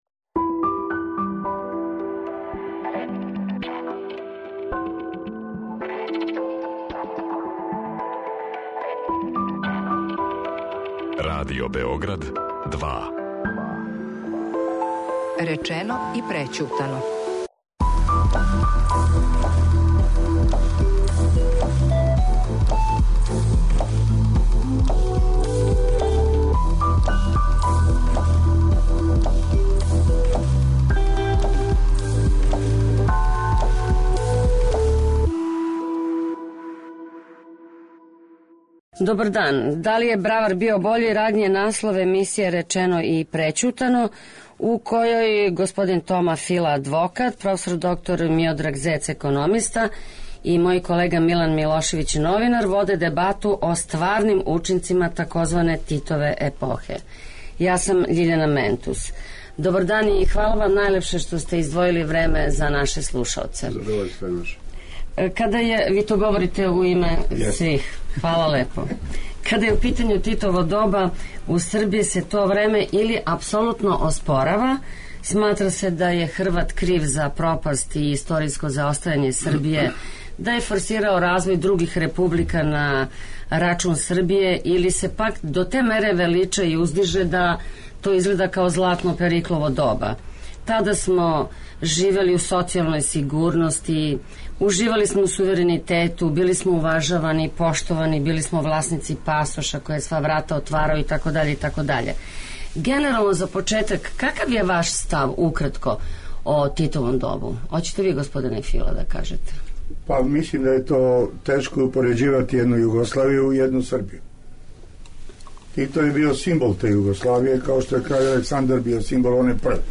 новинар дебатују о оствареним учинцима тзв. Титове епохе.